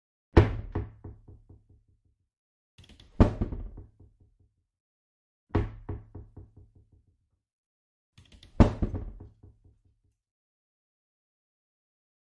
Kitchen Cabinets; Open / Close; Cabinet Open / Close With Bounce ( Four Times ). Medium Close Perspective.